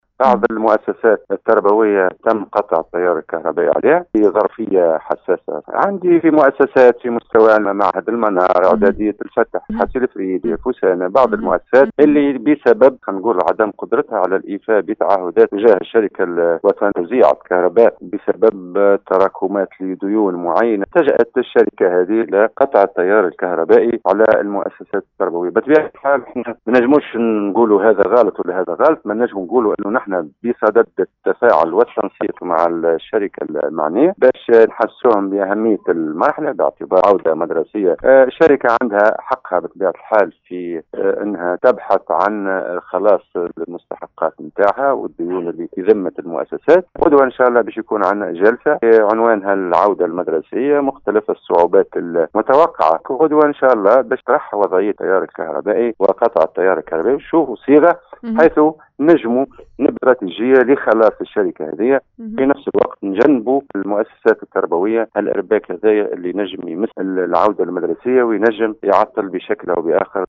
أكد  المندوب الجهوي للتربية بالقصرين  منصف القاسمي في تصريح لإذاعة السيليوم أف ام خلال برنامج “نهارك زين ” ان الشركة التونسية للكهرباء والغاز  قطعت التيار الكهربائي  على 6 مؤسسات تربوية في ولاية القصرين، بسبب تراكم الديون و عدم خلاص فواتير الكهرباء .